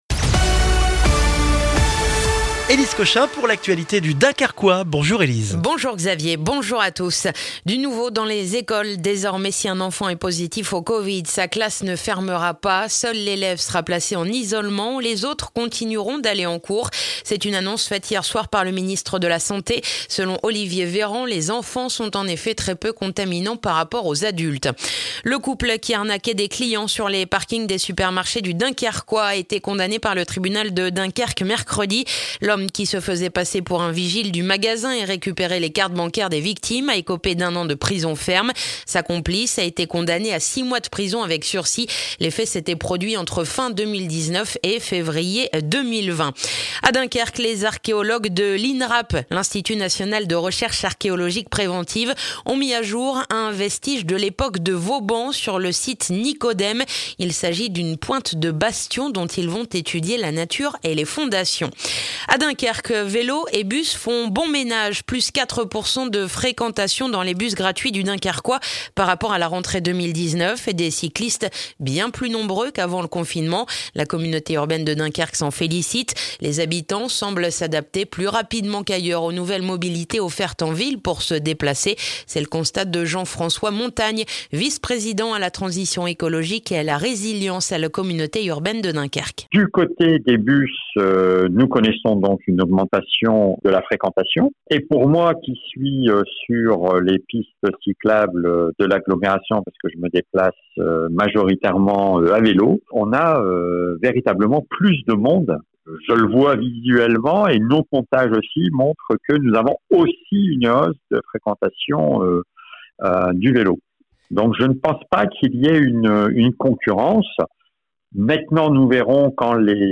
Le journal du vendredi 18 septembre dans le dunkerquois